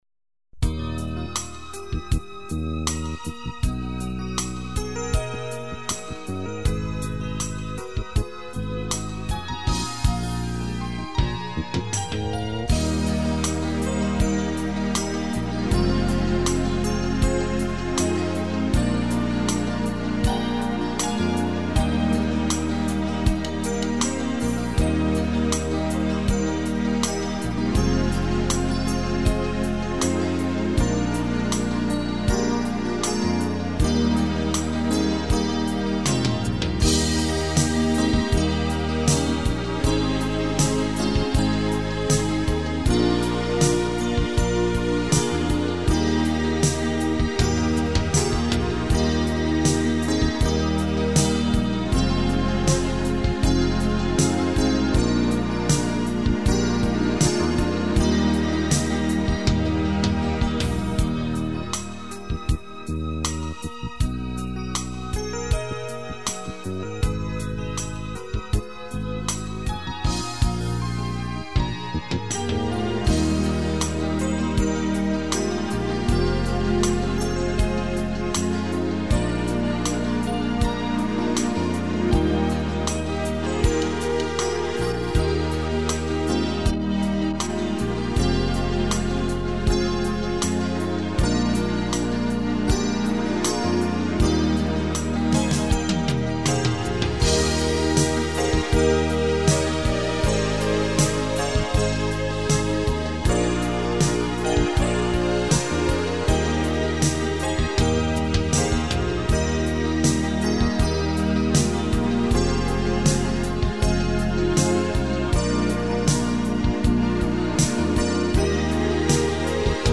Playbacks-KARAOKE